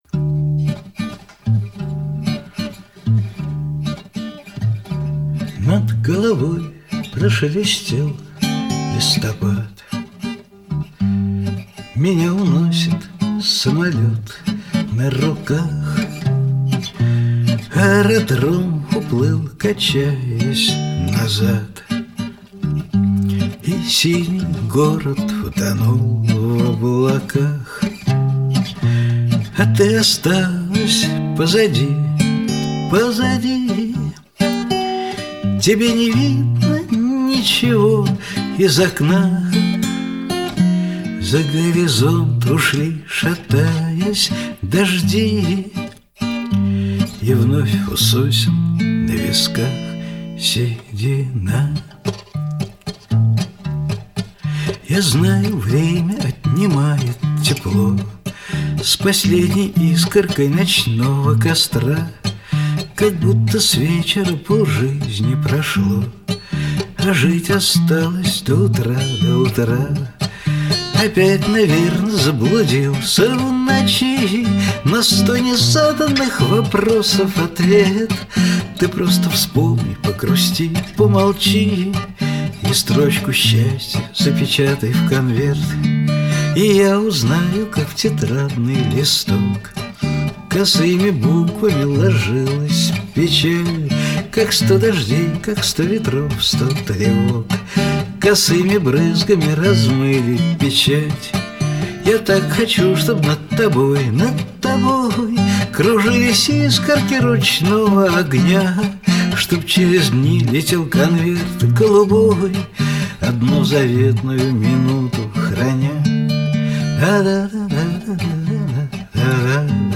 Алаверды в исполнении автора